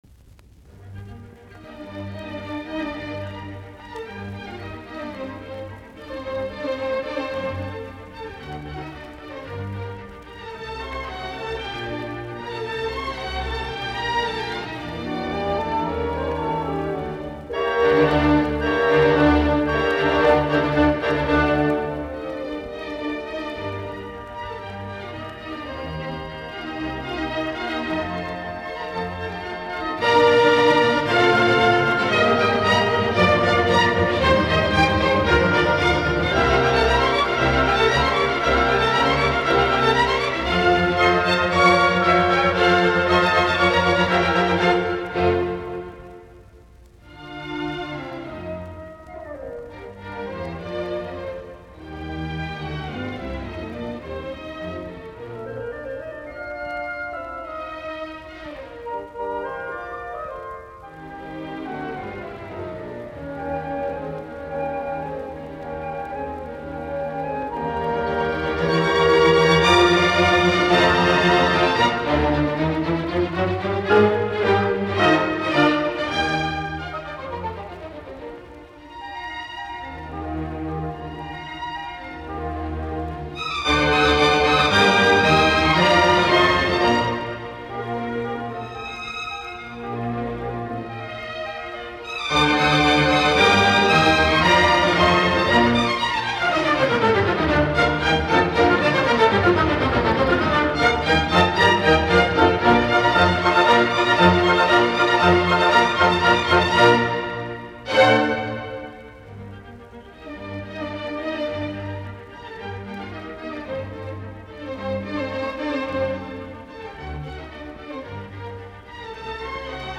Symphony no. 40 : in g minor, K. 550
Sinfoniat, nro 40, KV550, g-molli
Soitinnus: ork.